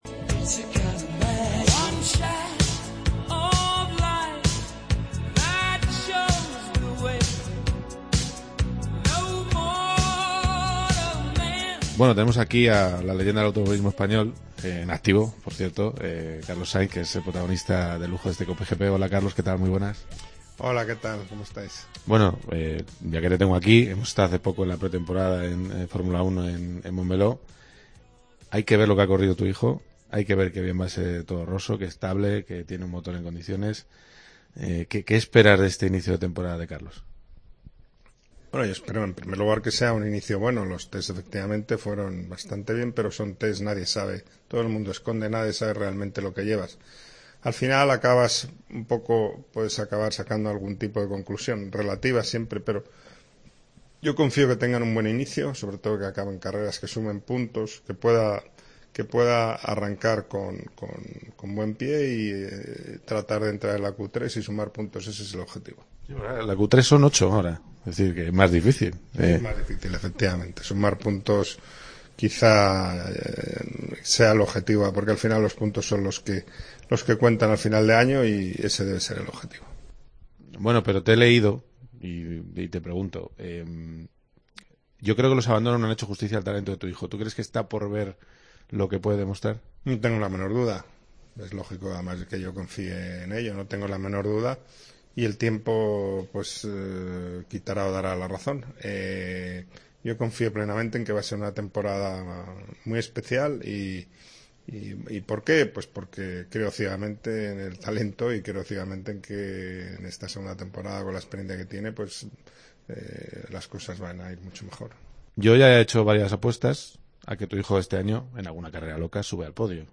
Carlos Sainz, en COPE GP: "Sería un subidón ver a mi hijo y a Alonso juntos en el podio"
El bicampeón del mundo de rallys habla en COPE GP de su actual proyecto con Peugeot en el mundo del motor y analiza la pretemporada de Fórmula 1 y el futuro de su hijo en Toro Rosso.